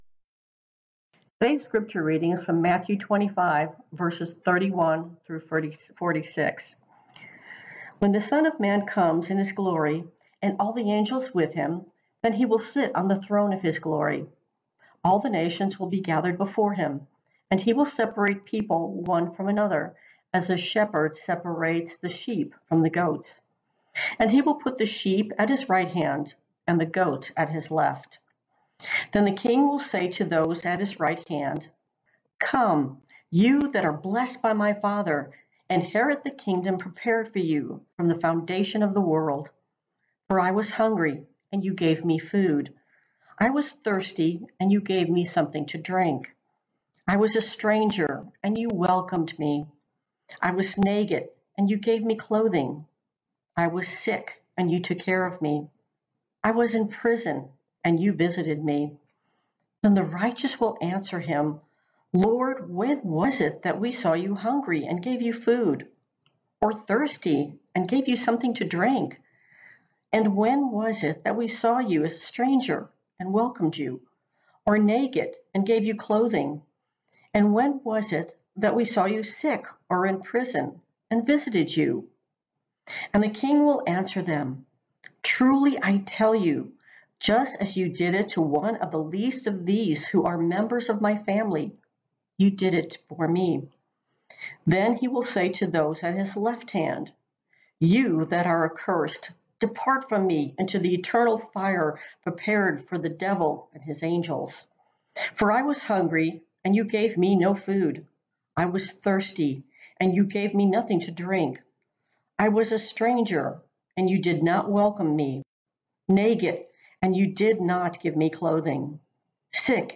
June 28, 2020 The End of the World as We Know it: Guest Preacher